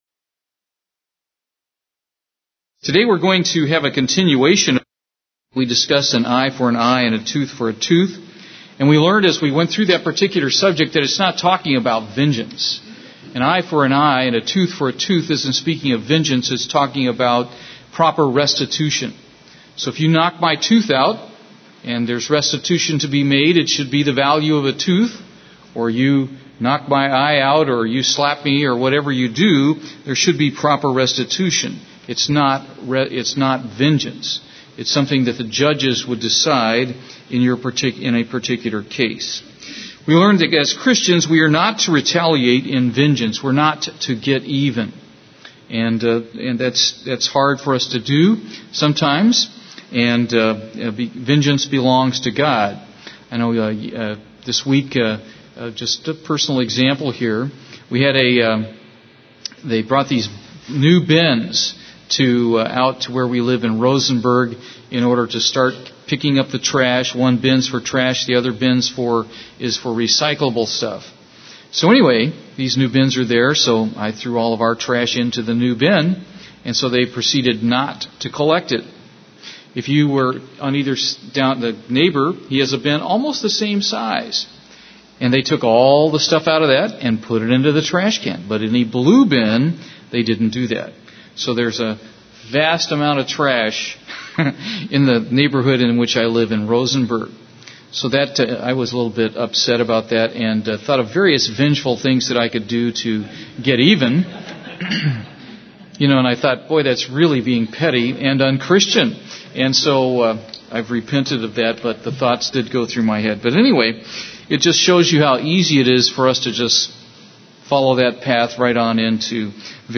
Given in Houston, TX
UCG Sermon Studying the bible?